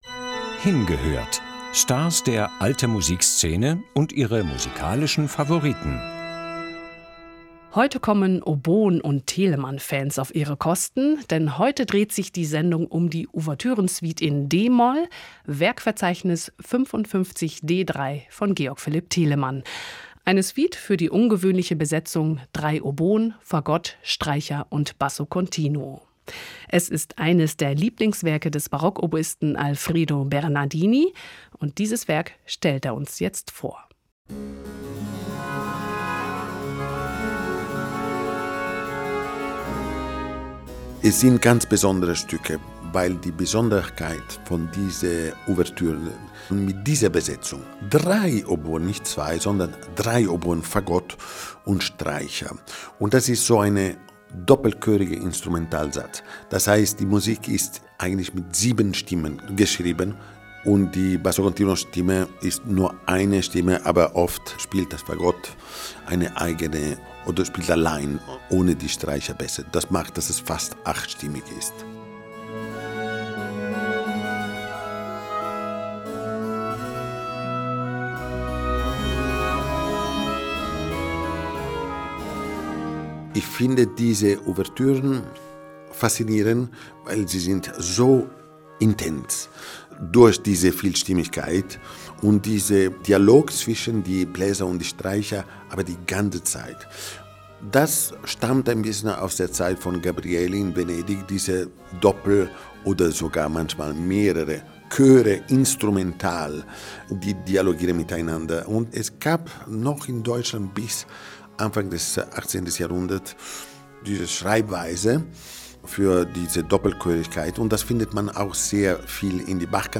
Hingehört – Stars der Alte-Musik-Szene und ihre musikalischen Favoriten